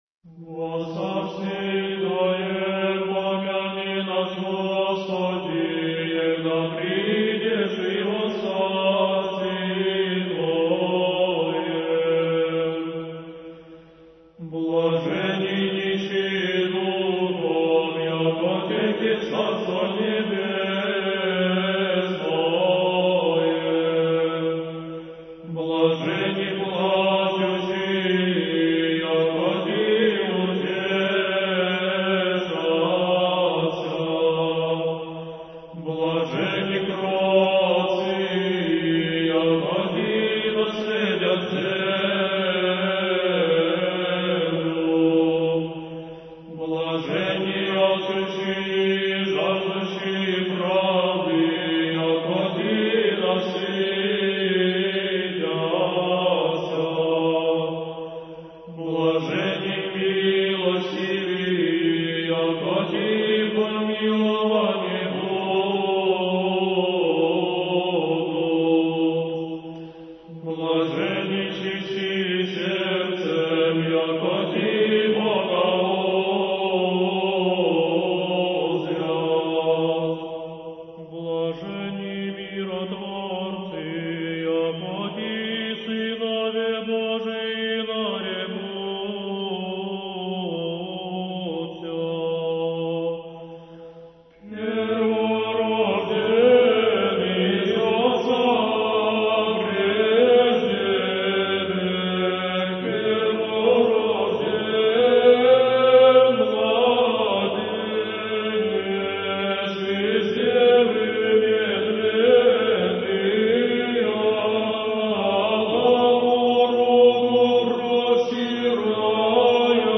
Архив mp3 / Духовная музыка / Русская / Ансамбль "Сретение" /